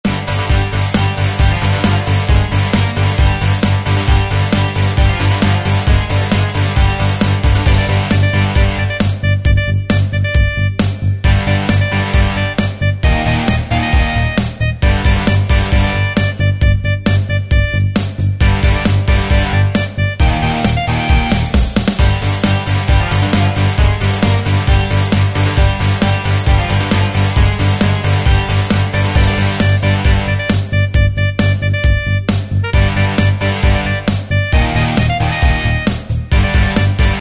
Tempo: 134 BPM.
MP3 with melody DEMO 30s (0.5 MB)zdarma